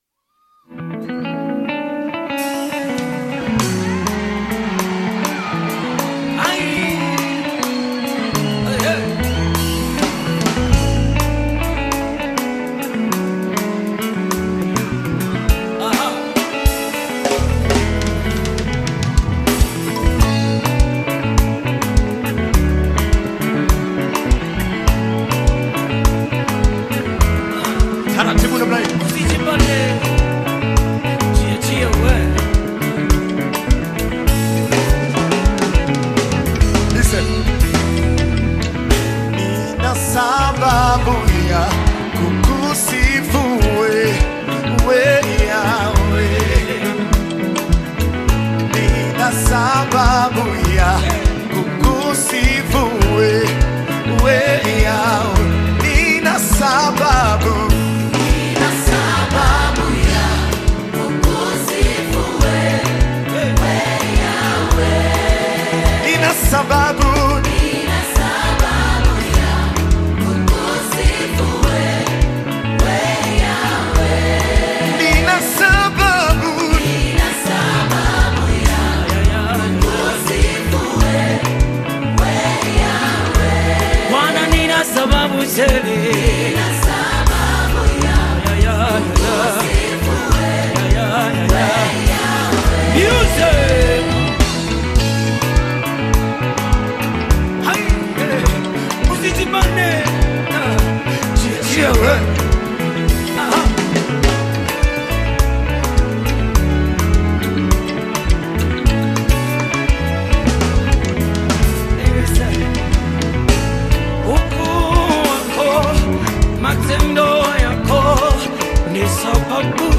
Gospel music track
Tanzanian Gospel music group